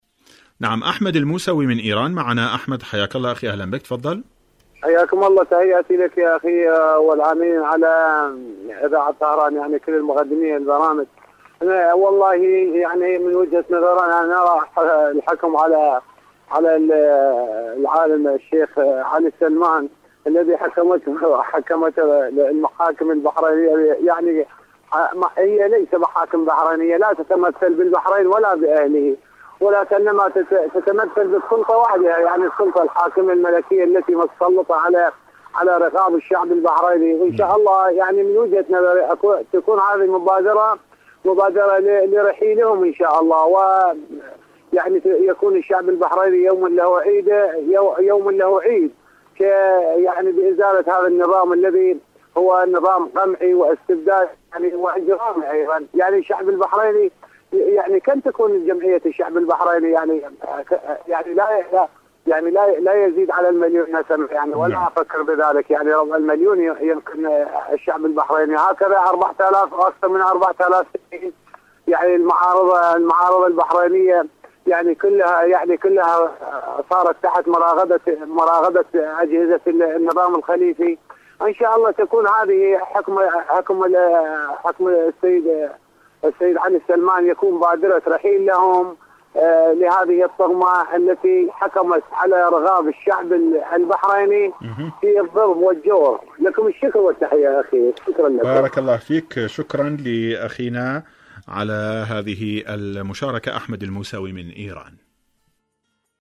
برنامج : بحرين الصمود / مشاركة هاتفية